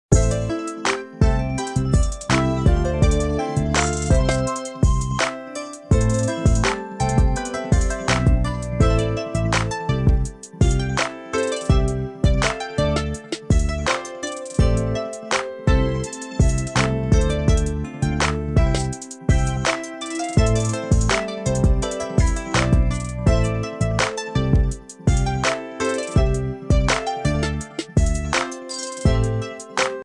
Efeito de batida Urbano